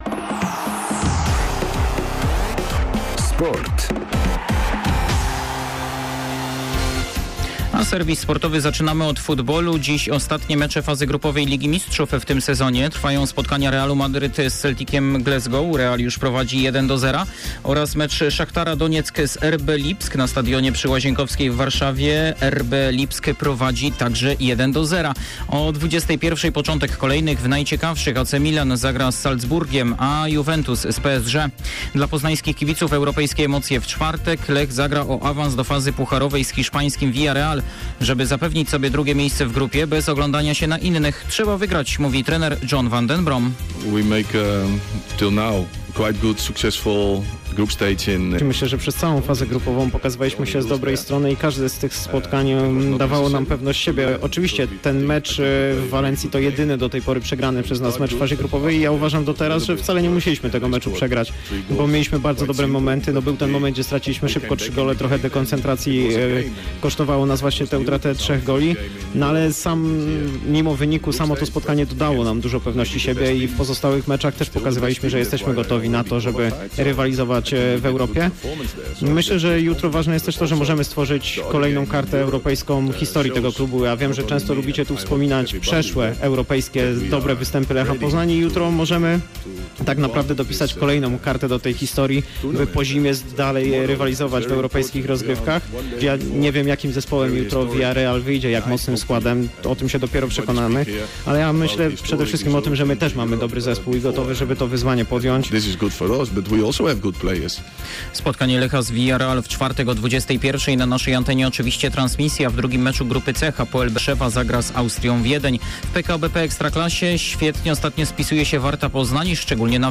02.11.2022 SERWIS SPORTOWY GODZ. 19:05